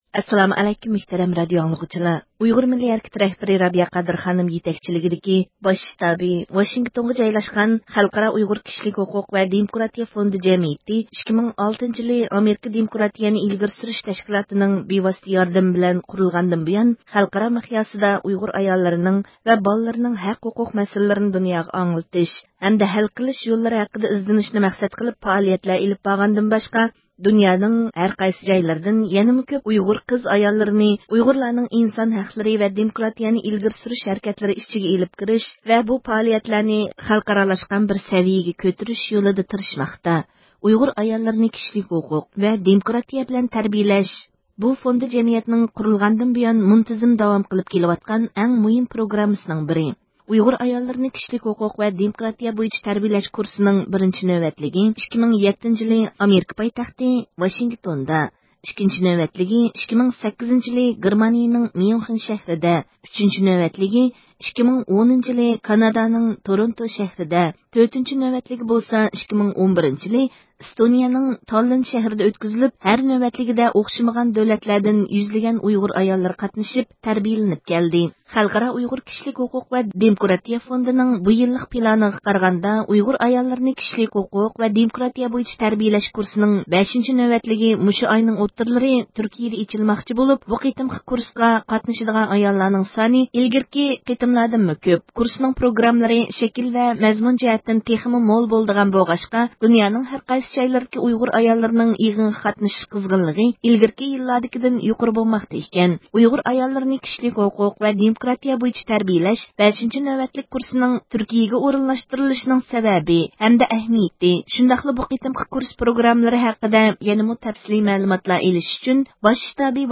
ئۇيغۇر ئاياللىرىنى كىشىلىك ھوقۇق ۋە دېموكراتىيە بويىچە تەربىيىلەش 5-نۆۋەتلىك كۇرسىنىڭ تۈركىيىگە ئورۇنلاشتۇرۇلۇشىنىڭ سەۋەبى ھەمدە ئەھمىيىتى شۇنداقلا بۇ قېتىمقى كۇرس پروگراممىلىرى ھەققىدە يەنىمۇ تەپسىلىي مەلۇمات ئېلىش ئۈچۈن، باش شتابى ۋاشىنگتوندىكى خەلقئارا ئۇيغۇر كىشىلىك ھوقۇق ۋە دېموكراتىيە فوندىنىڭ رەئىسى رابىيە قادىر خانىمنى زىيارەت قىلدۇق.